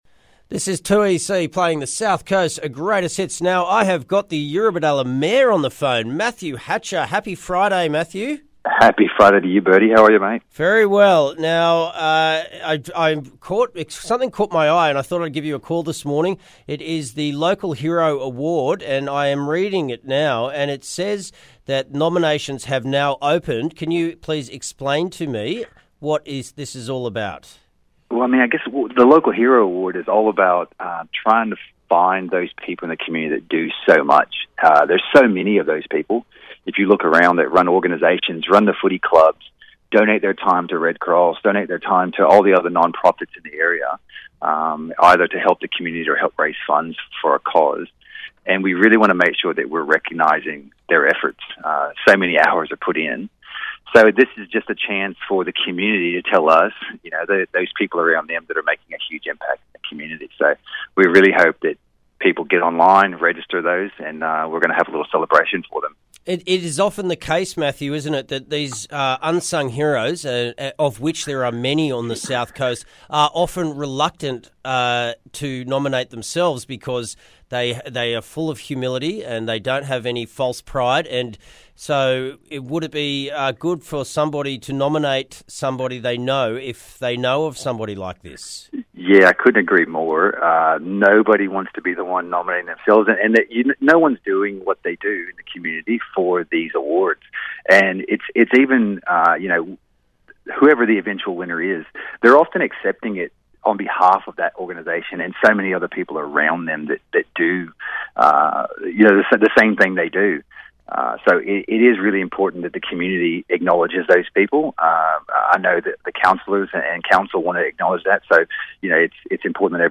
I spoke to the newly minted Eurobodalla Mayor Mathew Hatcher, a previous recipient of the award himself, about the wonderful and generous heroes of the south coast.